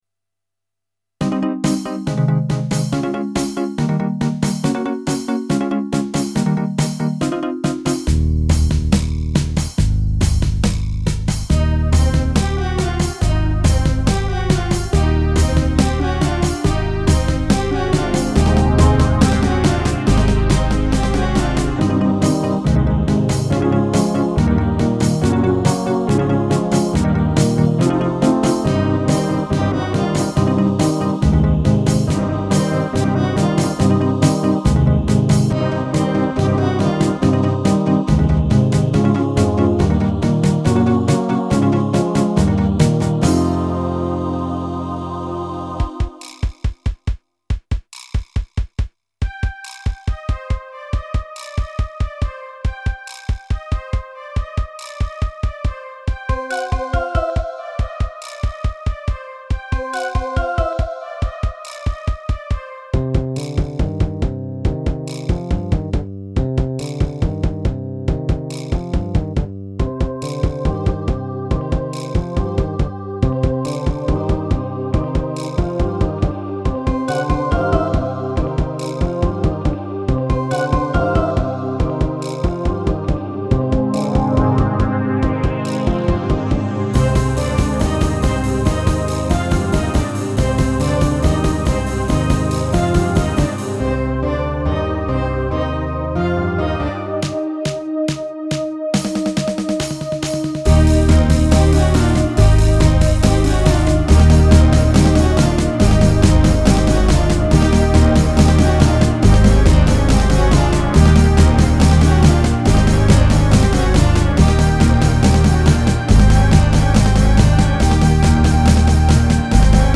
Solid infection rhythms with an international flavour